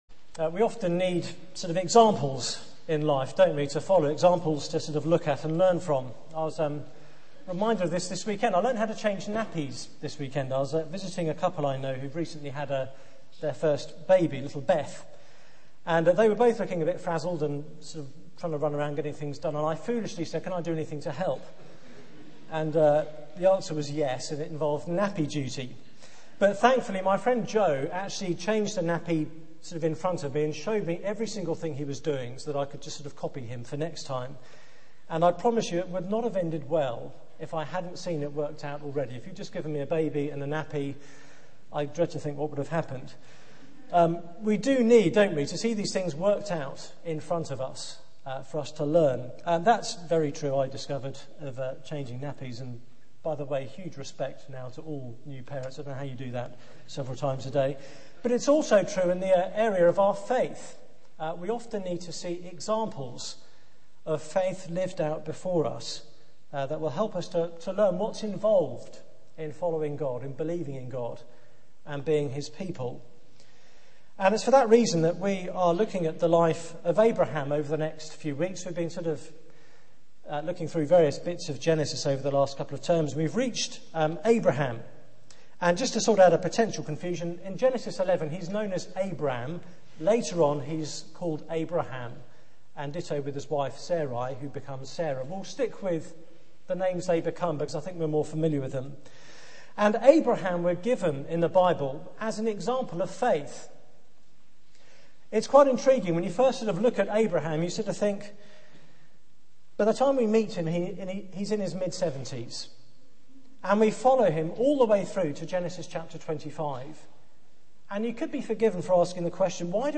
Media for 6:30pm Service on Sun 08th Nov 2009 18:30 Speaker: Passage: Genesis 12 Series: The Gospel According To Abraham Theme: The Call Sermon Search the media library There are recordings here going back several years.